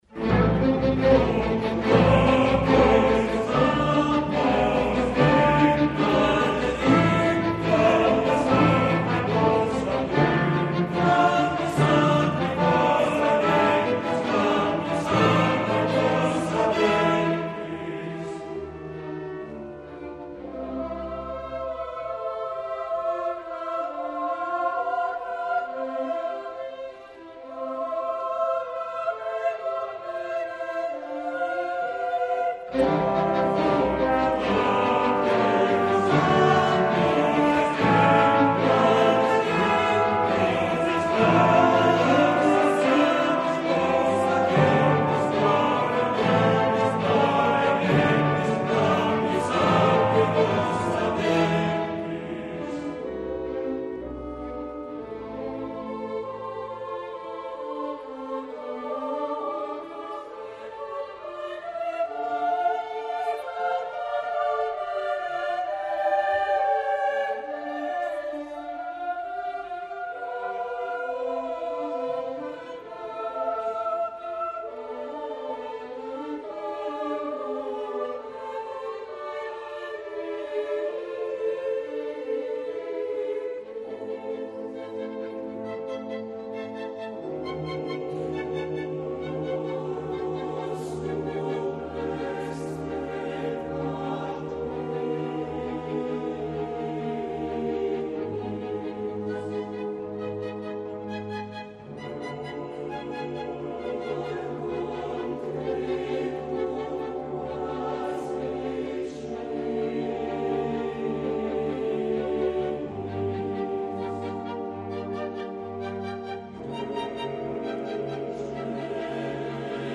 CD1 VOCI E STRUMENTI